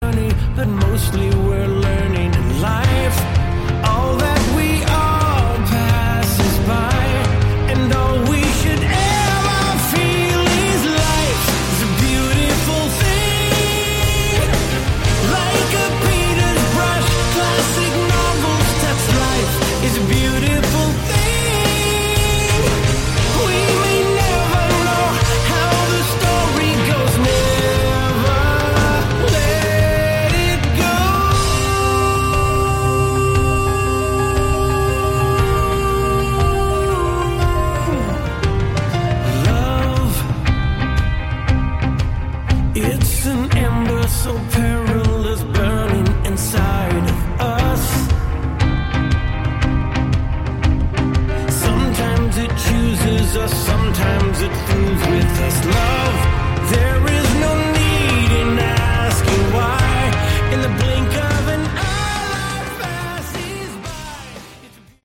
Category: Light AOR
saxophone
keyboards, vocals
guitar, vocals
drums
bass